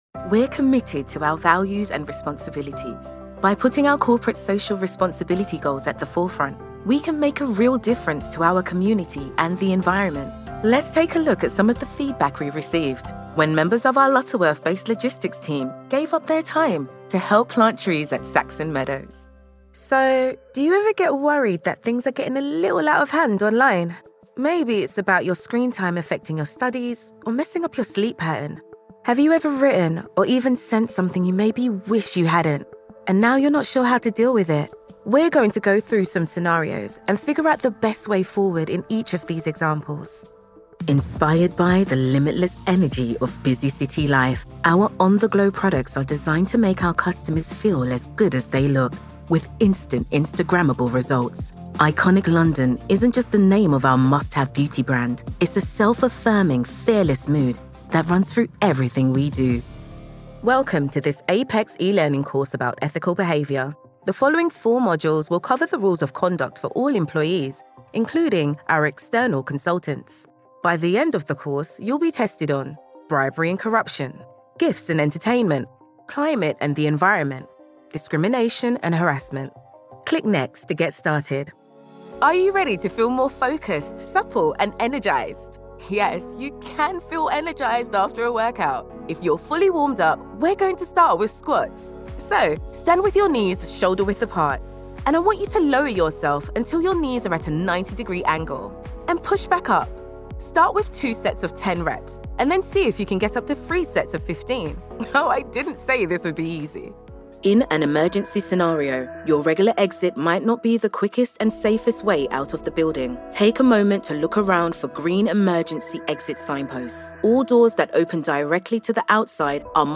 Anglais (Britannique)
Naturelle, Distinctive, Enjouée
Corporate